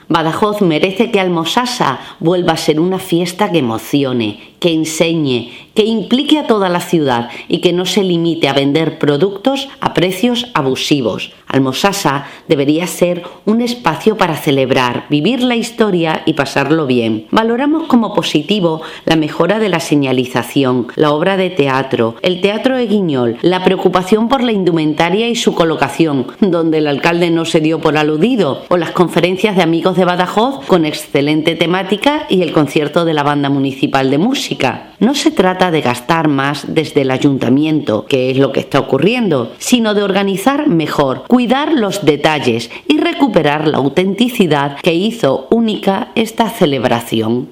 “Badajoz merece que Al-Mossassa vuelva a ser una fiesta que emocione, que enseñe, que implique a toda la ciudad y que no se limite a vender productos a precios abusivos”, ha declarado Silvia González, portavoz socialista en el Ayuntamiento para la que “esta celebración tiene un potencial enorme que no se está aprovechando”.